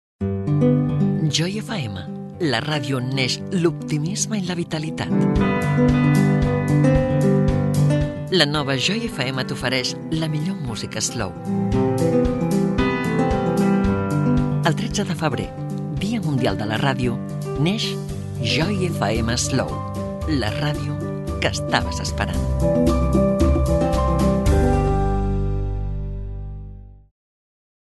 Anunci de l'estrena de la nova programació musical Joy FM Slow el Dia Mundial de la ràdio del 2021